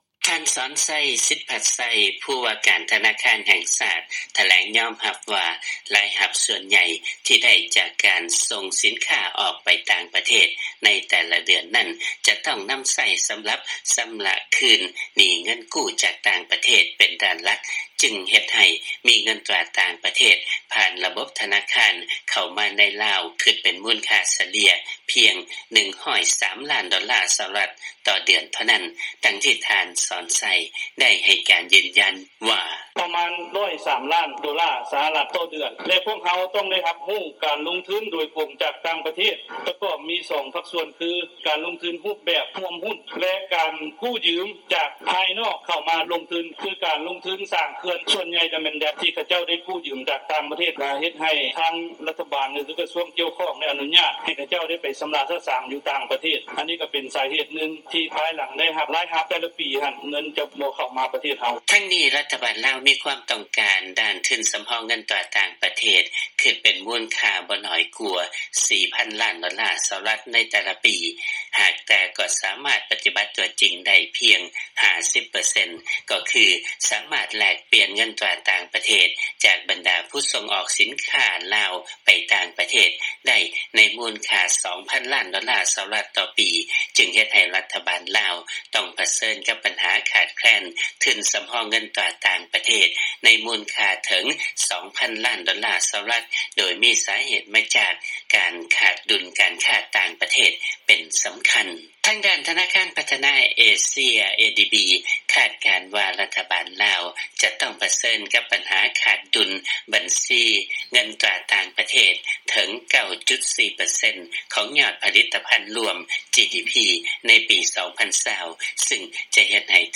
ຟັງລາຍງານ ຜູ້ວ່າການທະນາຄານ ແຫ່ງຊາດ ຍອມຮັບວ່າ ທະນາຄານໃນ ລາວ ໄດ້ຮັບເງິນໂອນຈາກຕ່າງປະເທດພຽງ 103 ລ້ານໂດລາ ຕໍ່ເດືອນ